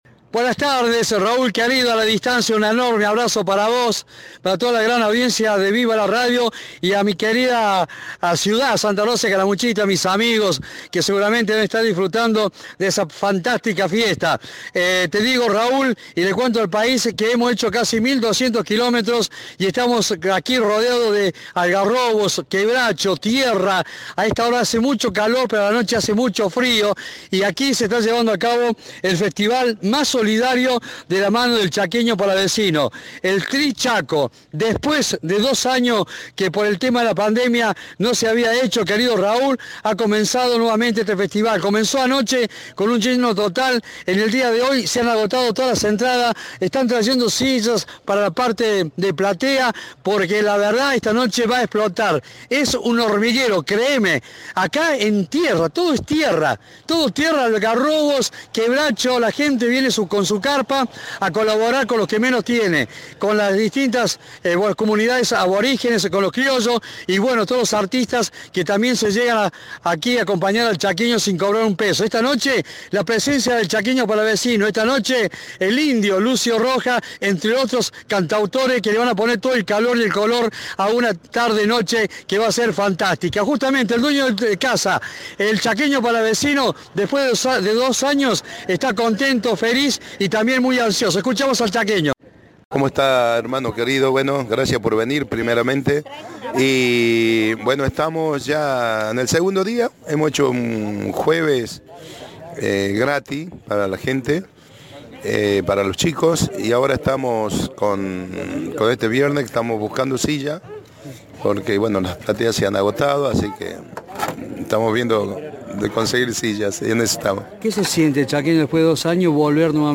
Desde Santa Victoria Este
Cadena 3, una vez más en el Festival del Trichaco